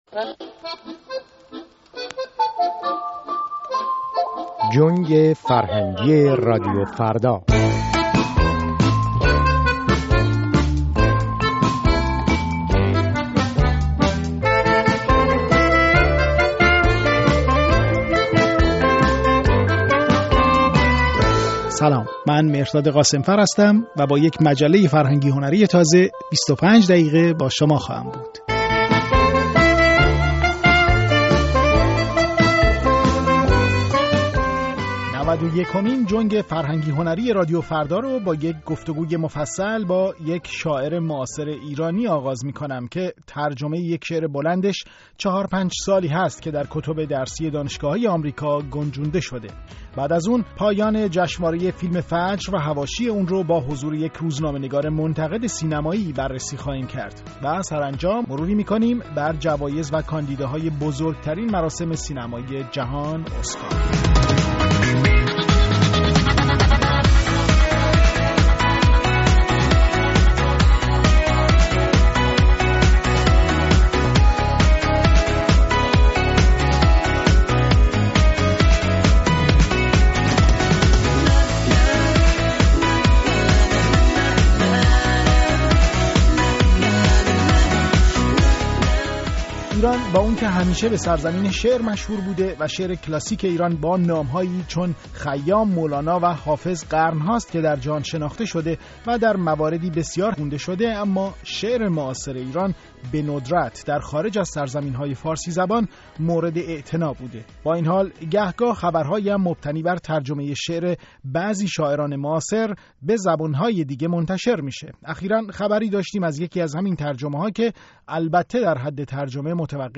جنگ فرهنگی و هنری رادیو فردا؛ گفت‌وگو